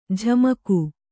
♪ jhamaku